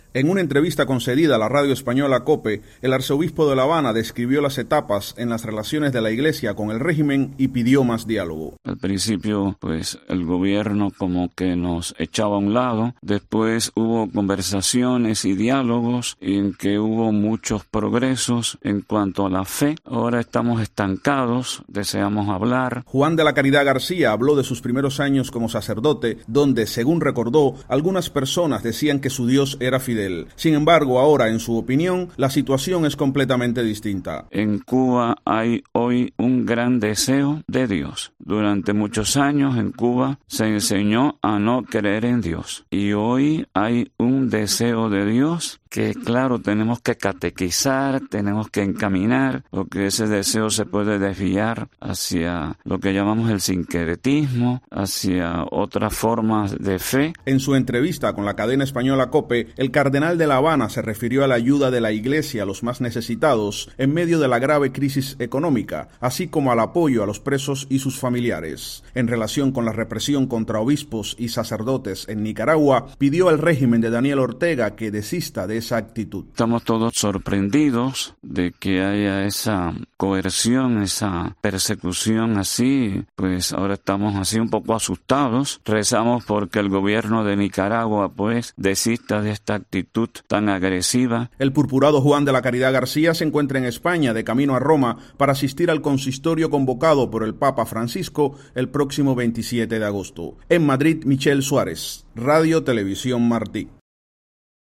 Reporte
desde Madrid